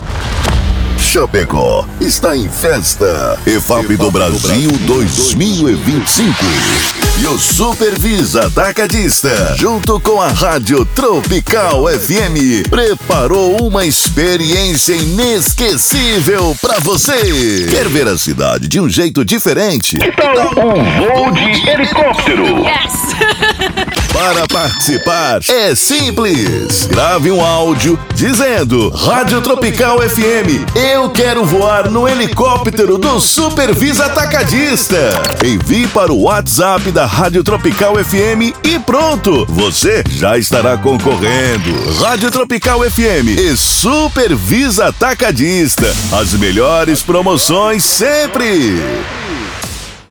Spot Tropical Fm e SuperViza - Promo Helicóptero: